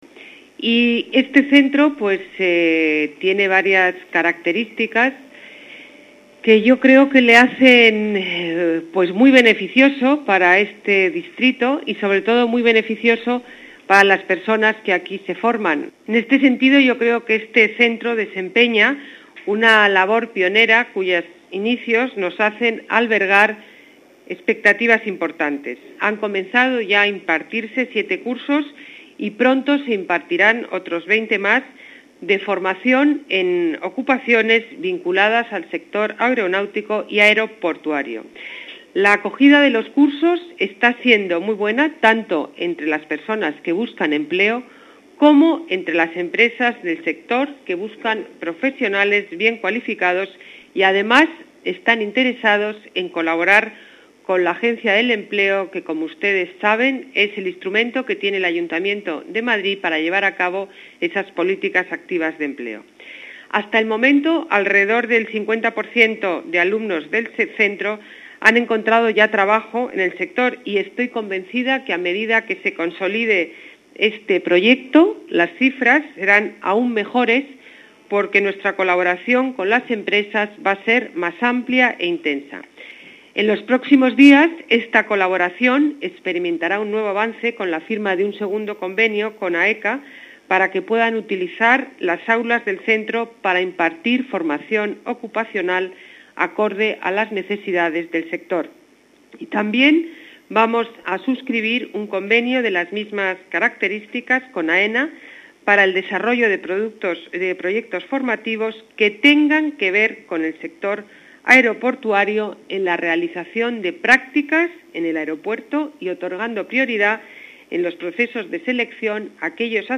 Nueva ventana:Declaraciones de Ana Botella durante la inauguración del Centro de Formación Ocupacional de Barajas